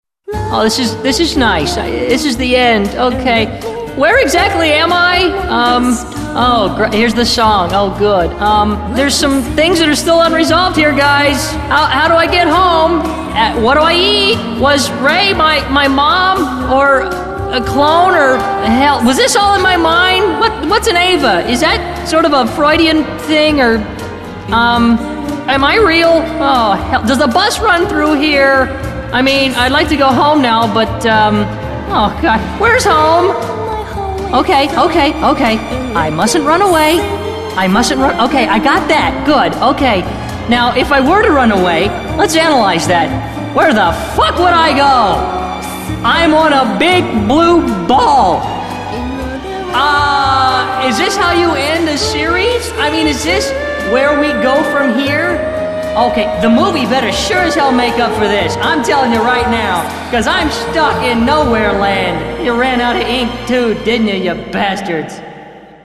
I love this rant so much.